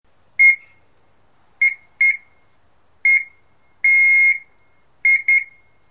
Download Ebike Horn sound effect for free.
Ebike Horn